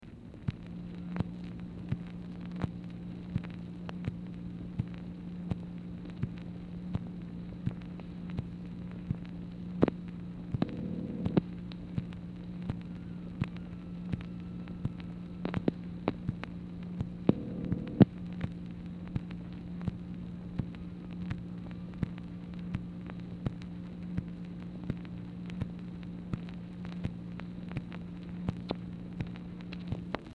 MACHINE NOISE
Format Dictation belt
Series White House Telephone Recordings and Transcripts